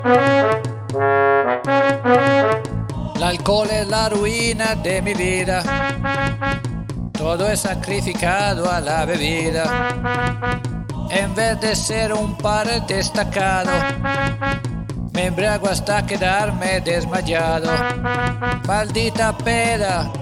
Tips to EQ and mix my first ever home-recorded song
Here's some info: - genre: Mexican cumbia - 4 tracks: voice, drums, bass, brass (trumpet + trombone) - DAW: Audacity (plus any free Linux plugin I might need) What I'd like to hear your two cents about: - Tips to EQ each...
I think the percussion could come up a fair bit, and the main vocal down.
Click to expand... followed your suggestions and remixed the track.